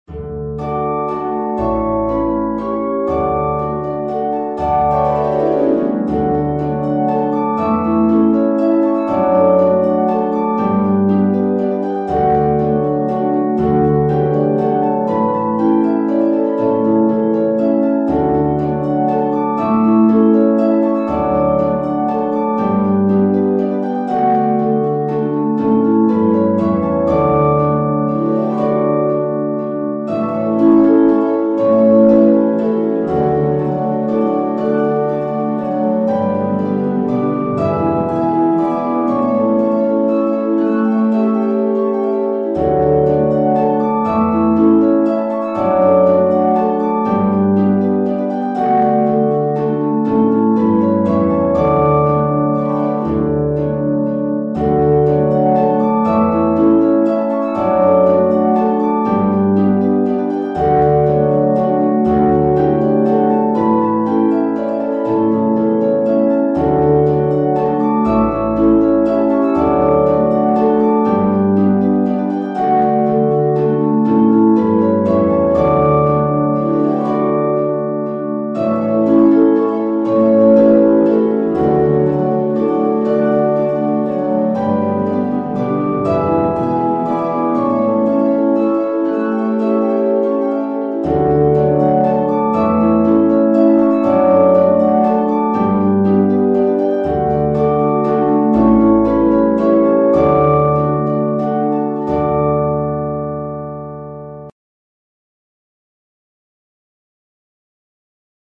A four harp, multi-playing level arrangment.